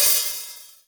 CM_HAT_OP.wav